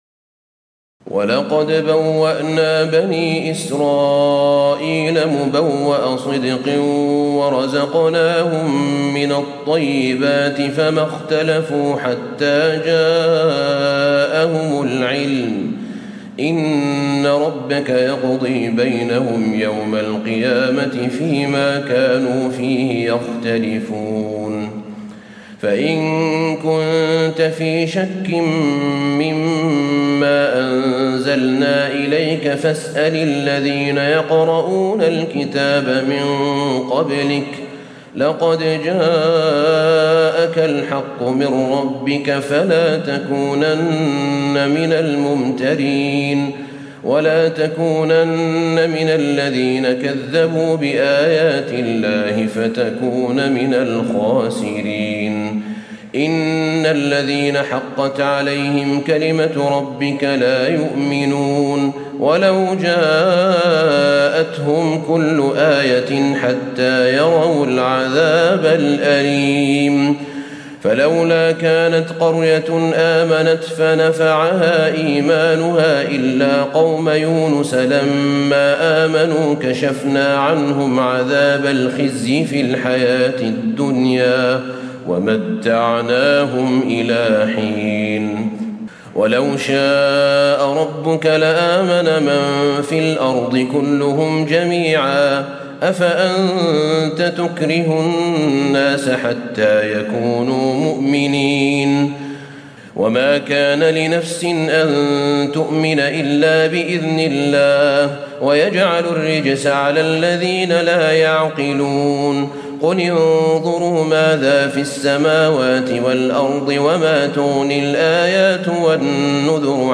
تراويح الليلة الحادية عشر رمضان 1436هـ من سورتي يونس (93-109) وهود (1-95) Taraweeh 11 st night Ramadan 1436H from Surah Yunus and Hud > تراويح الحرم النبوي عام 1436 🕌 > التراويح - تلاوات الحرمين